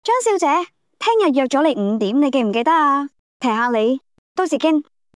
按此 :收聽 項目例子 (1) _ AI 語音